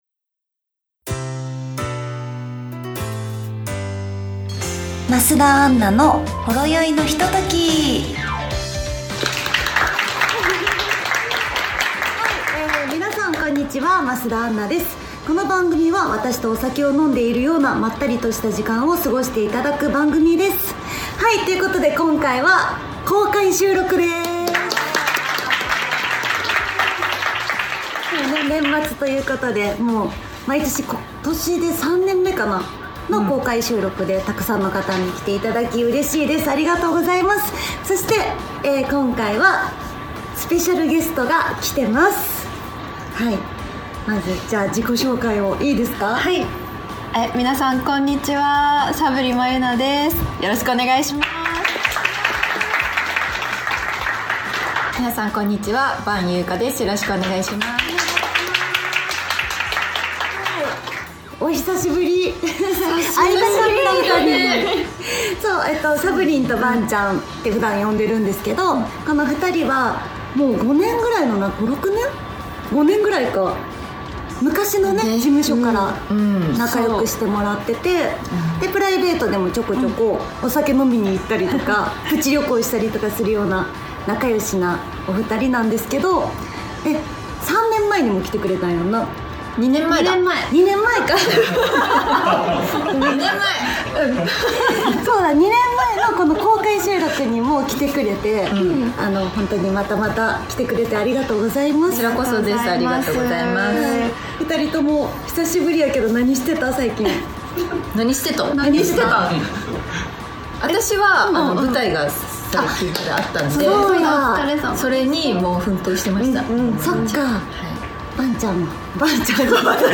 今回は年末恒例となった公開収録です！